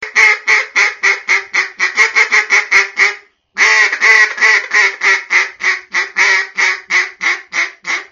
دانلود آهنگ اردک وحشی از افکت صوتی انسان و موجودات زنده
دانلود صدای اردک وحشی از ساعد نیوز با لینک مستقیم و کیفیت بالا
جلوه های صوتی